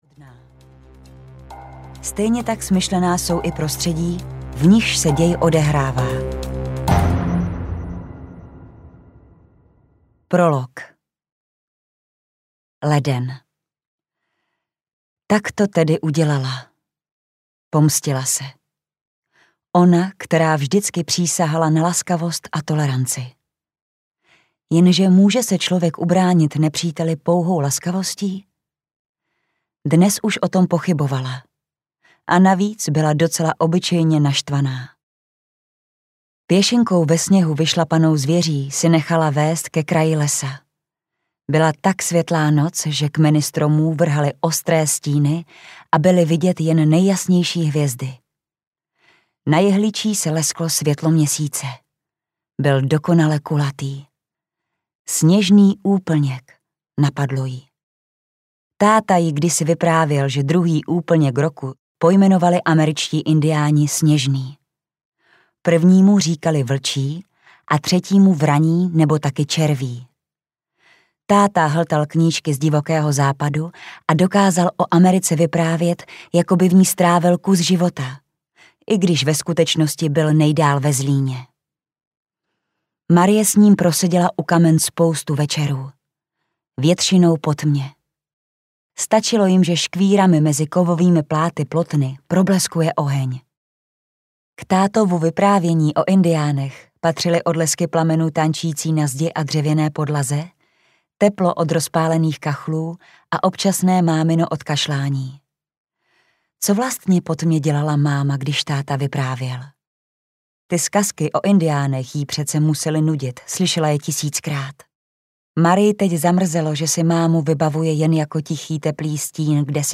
Sněžný měsíc audiokniha
Ukázka z knihy